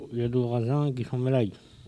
collecte de locutions vernaculaires
Catégorie Locution